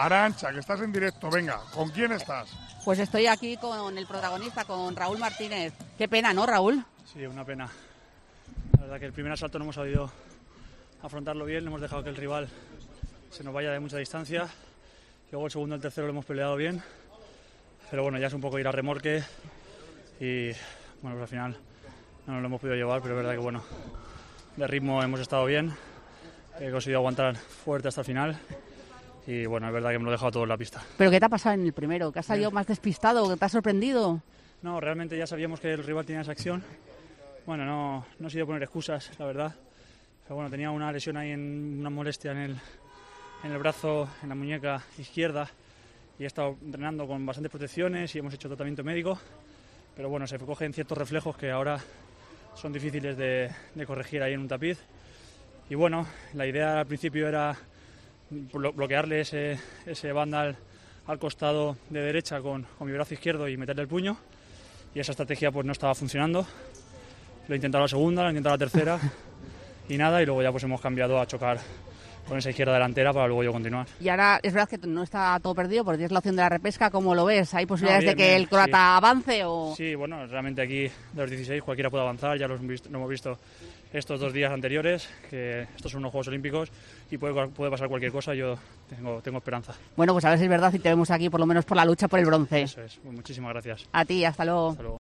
El taekwondista ha caído en primera ronda tras un mal primer asalto y, tras el combate, ha valorado esa derrota en el micrófono de la Cadena COPE.
Con Paco González, Manolo Lama y Juanma Castaño